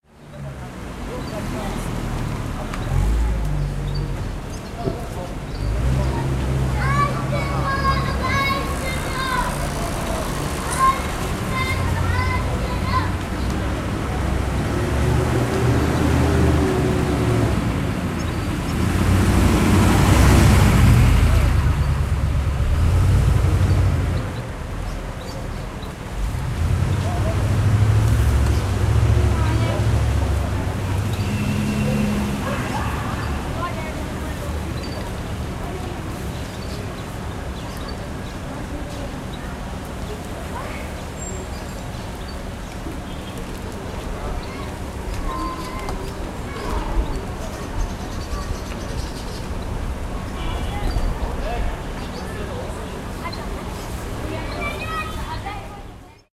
syriast-ambient_outdoors.ogg